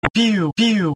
Zvuk-muzhskim-golosom-piu-piurakura
• Категория: Пи Пи Пи